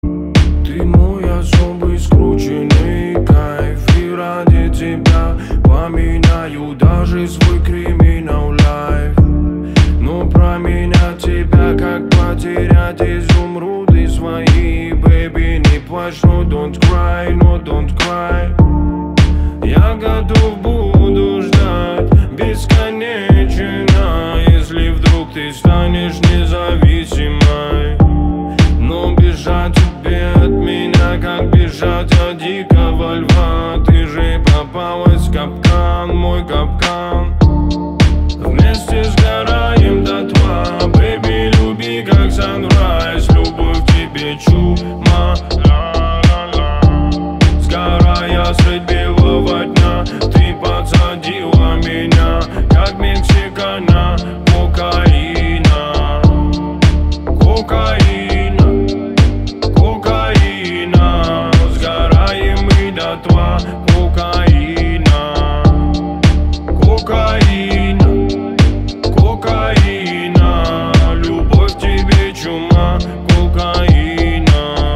• Качество: 320, Stereo